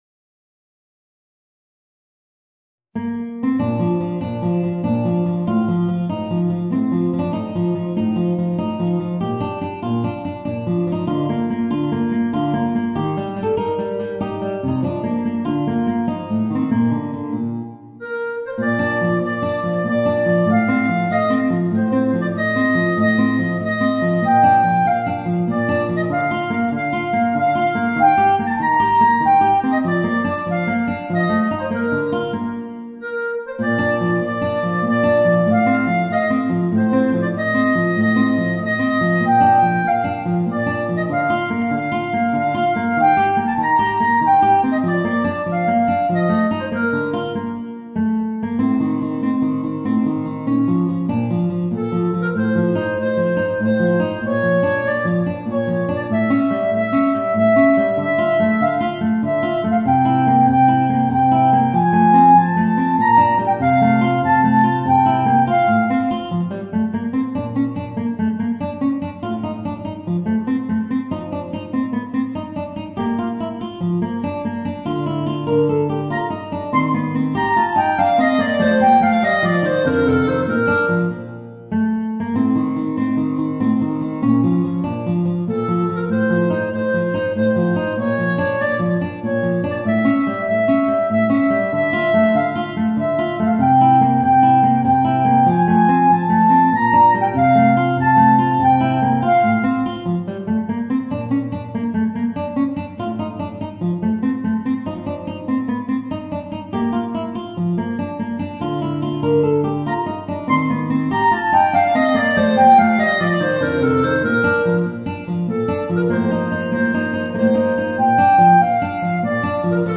Voicing: Clarinet and Guitar